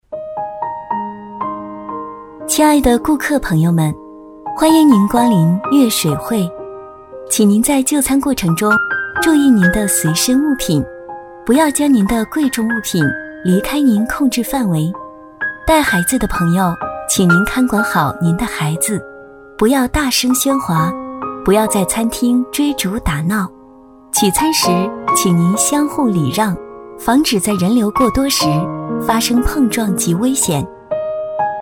女B25-广播提示-餐厅
女B25-广播提示-餐厅.mp3